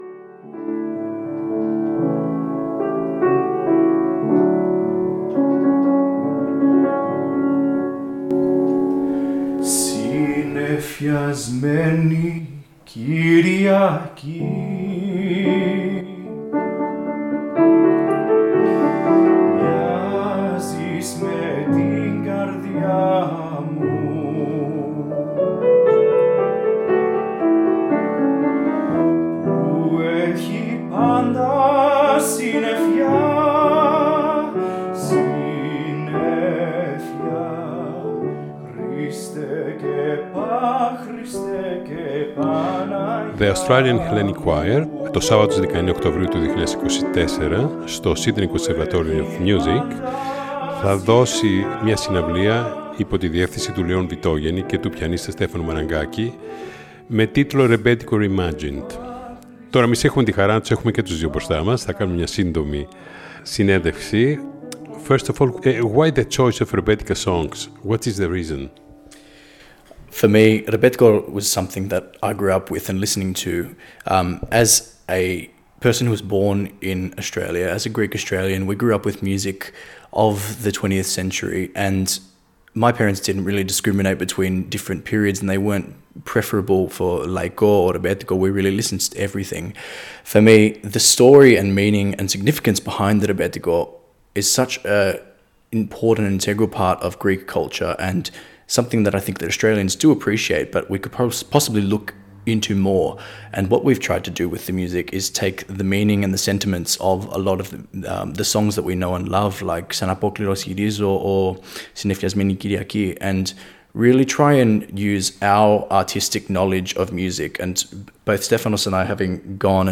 συνέντευξη που μεταδόθηκε στην ραδιοφωνική εκπομπή ” Νυκτερινοί Περίπατοι